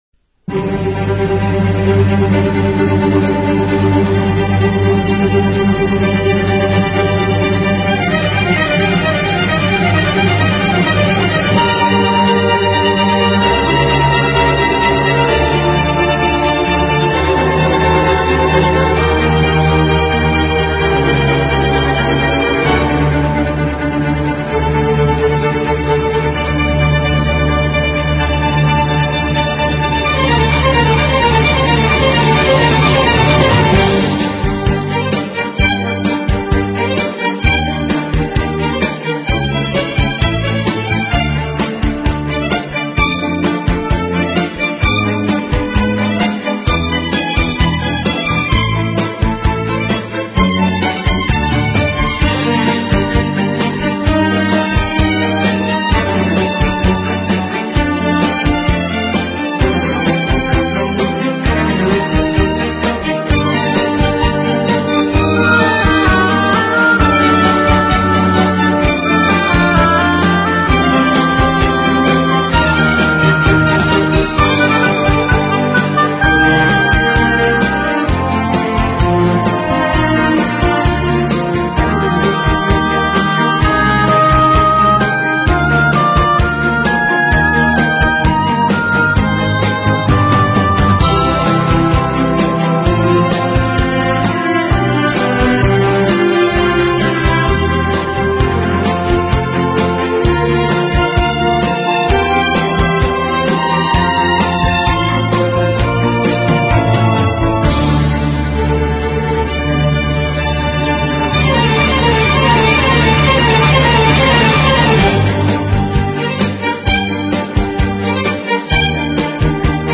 音乐风格： 其他|古典|Neo Classical，室内乐